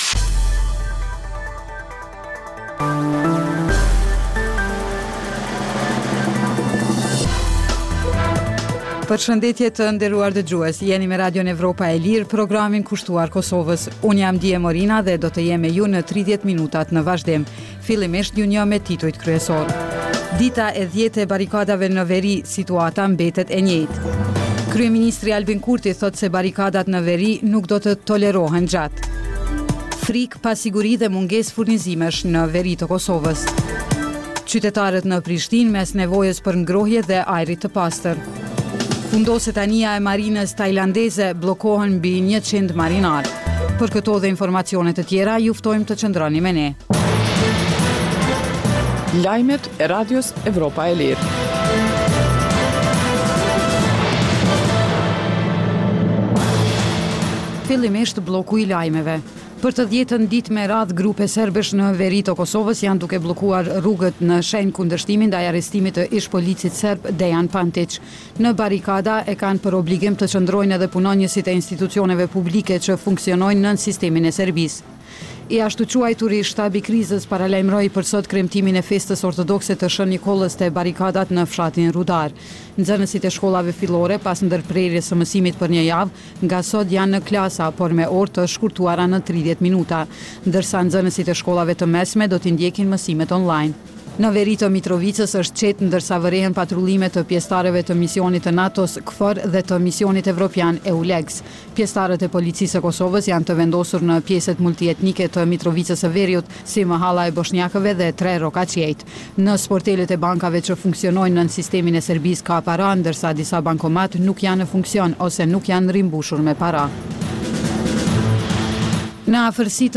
Emisioni i orës 16:00 është rrumbullaksim i zhvillimeve ditore në Kosovë, rajon dhe botë. Rëndom fillon me kronikat nga Kosova dhe rajoni, dhe vazhdon me lajmet nga bota. Kohë pas kohe, në këtë edicion sjellim intervista me analistë vendorë dhe ndërkombëtarë për zhvillimet në Kosovë.